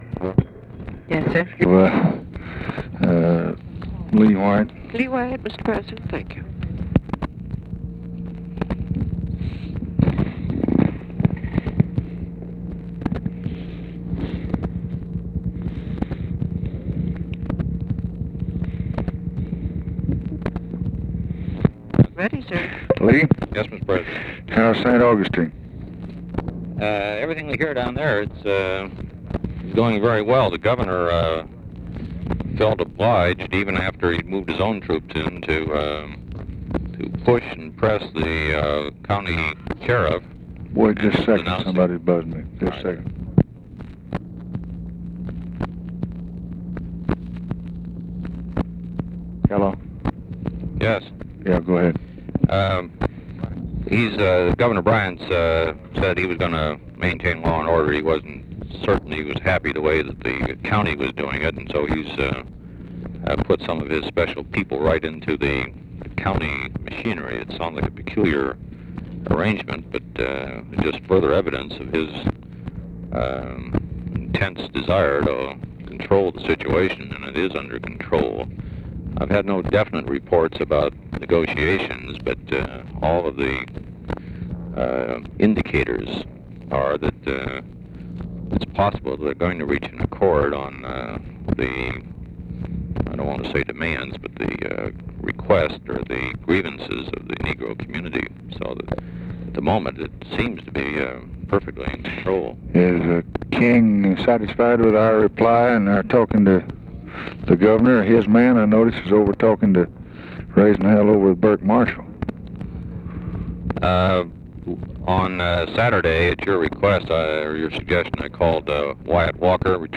Conversation with LEE WHITE, June 16, 1964
Secret White House Tapes